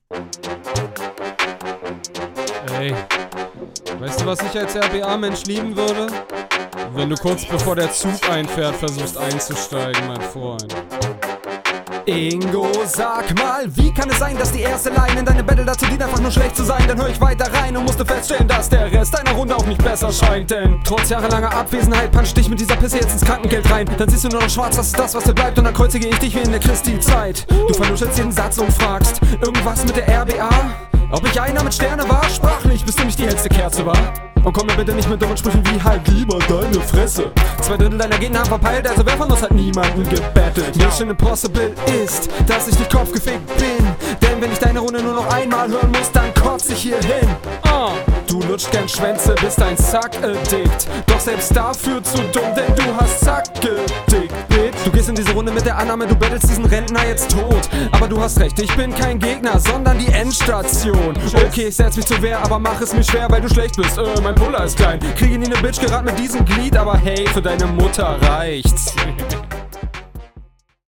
Mir gefällt der Flow deutlich besser als beim Gegner.